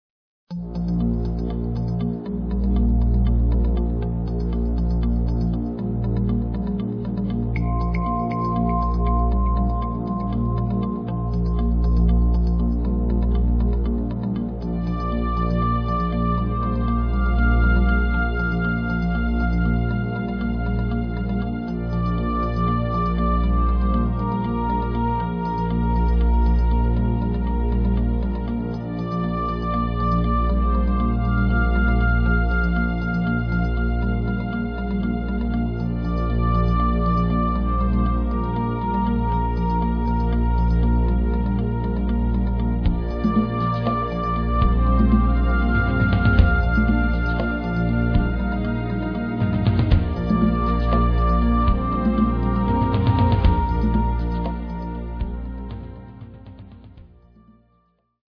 Electro-acoustic